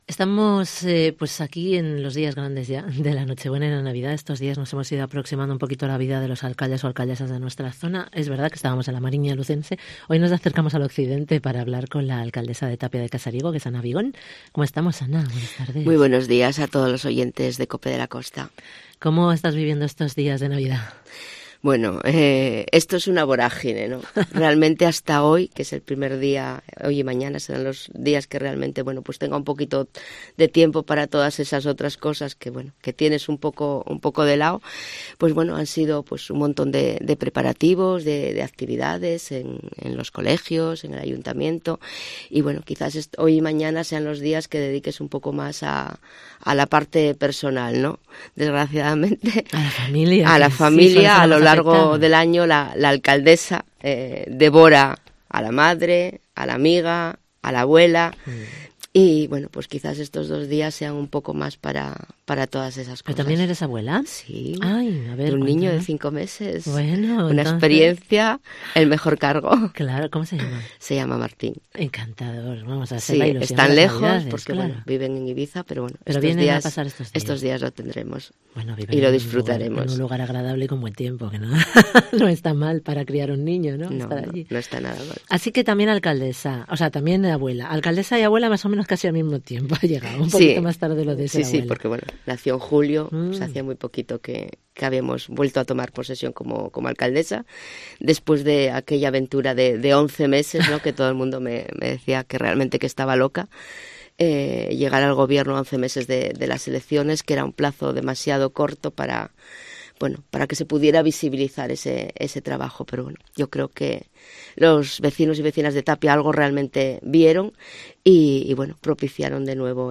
ENTREVISTA con Ana Vigón, alcaldesa de Tapia